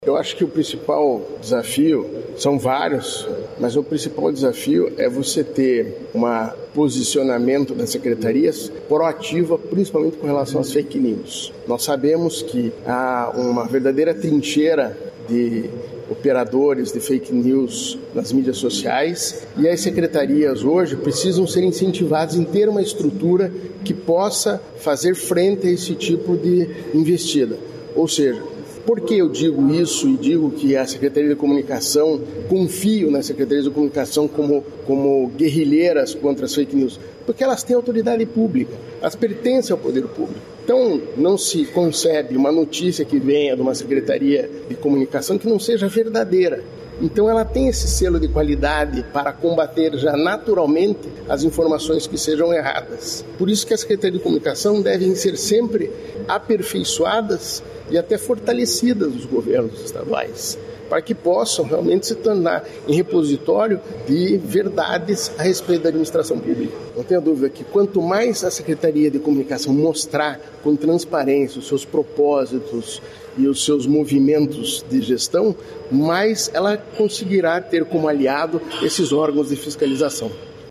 Sonora do conselheiro do TCE-PR, Ivan Bonilha, sobre a realização, no Paraná, do 3º Fórum Nacional das Secretarias de Comunicação